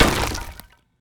wood_break.ogg